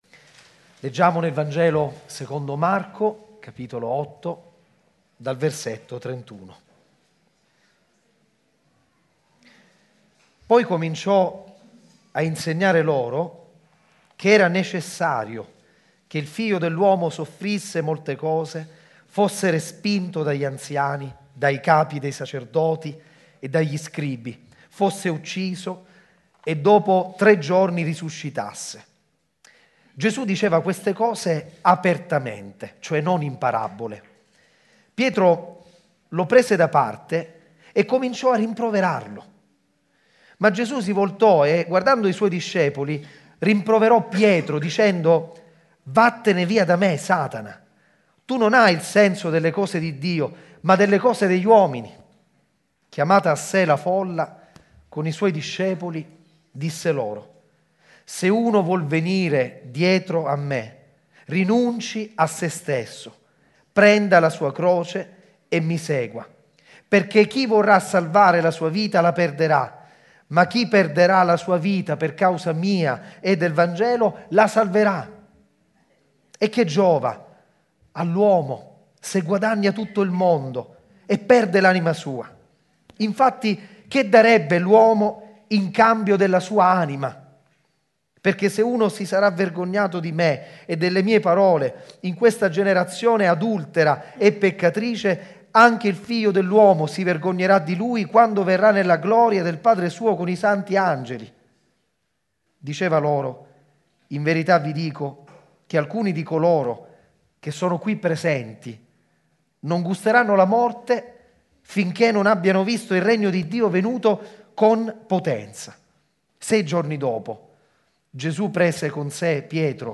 sermone4.mp3